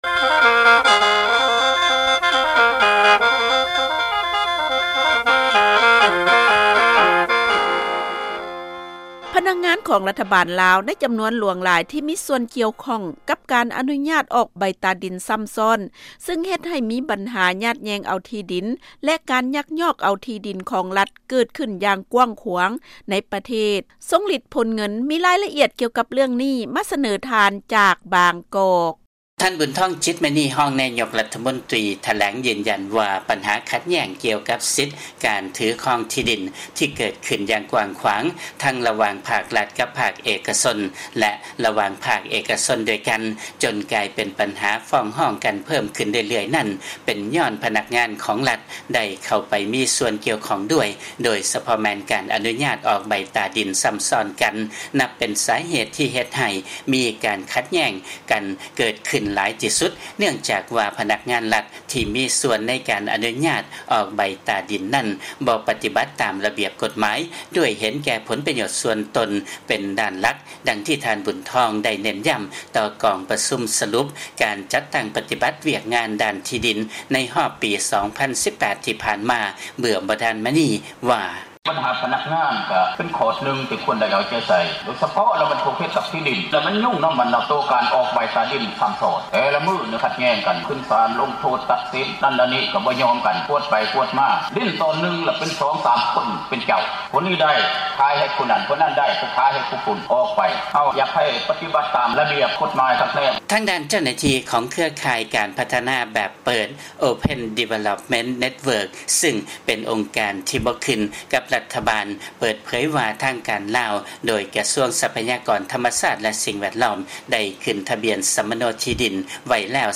ຟັງລາຍງານ ພະນັກງານ ລັດຖະບານ ລາວ ຈຳນວນຫຼາຍ ມີສ່ວນກ່ຽວຂ້ອງ ກັບການອະນຸຍາດ ອອກໃບຕາດິນຊ້ຳຊ້ອນ